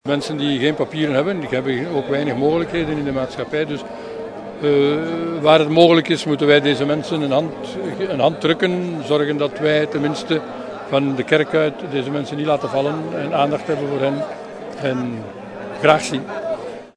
Aan de bezetting ging een fakkeltocht vooraf die begon aan de Sint-Baafs kerk in hartje Gent. De Gentse bisschop Luc Van Looy die in de kathedraal een mis voordroeg kwam de actievoerders na afloop groeten.